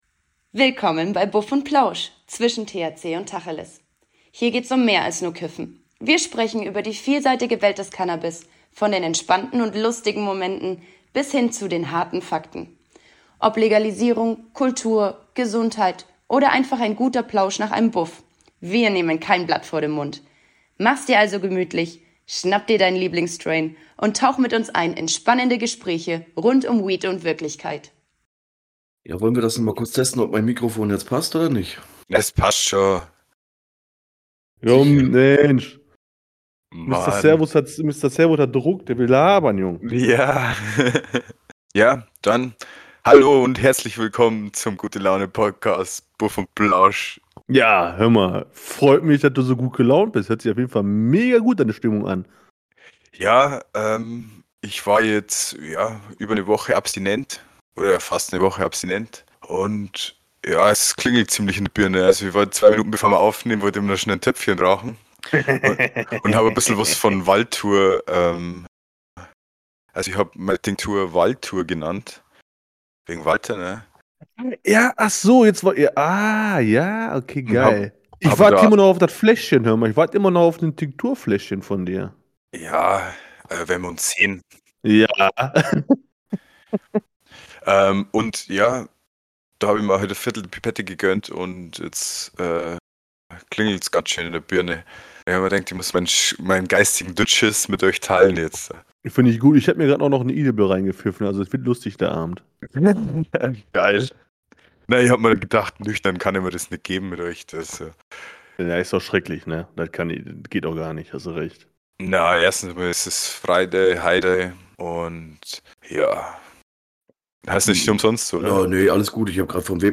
In dieser Folge starten wir ganz entspannt und locker, bevor wir uns mit unseren Podcast-Freunden von Bibisbubatz an die Mikrofone setzen.
Es gibt keine großen Themen, nur Gelächter, spontane Gedanken und vielleicht die ein oder andere witzige Anekdote.